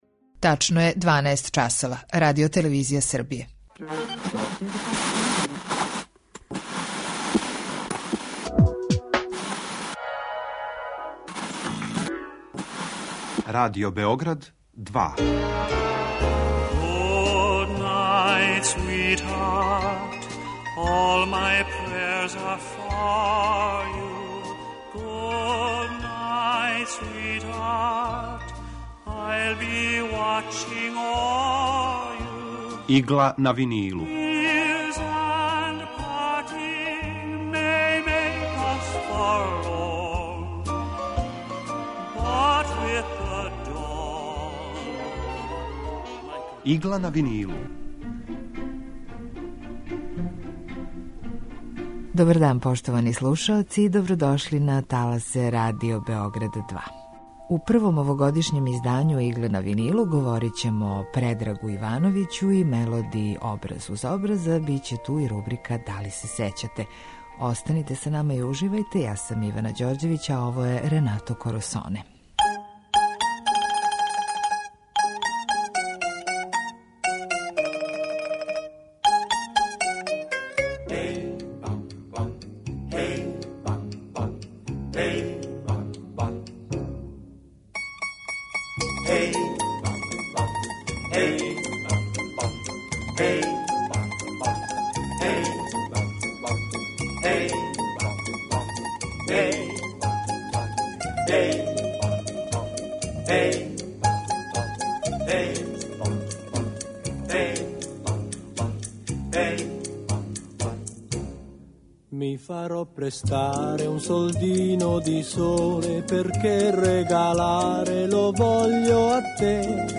У Игли на винилу представљамо одабране композиције евергрин музике од краја 40-их до краја 70-их година 20. века. Свака емисија садржи кроки композитора / извођача и рубрику Два лица једног хита (две верзије исте композиције)